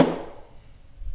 acoustic household percussion sound effect free sound royalty free Memes